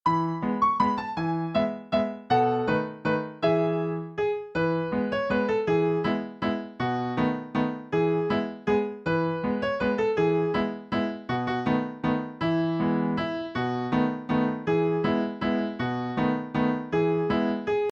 Traditional Children's Song Lyrics and Sound Clip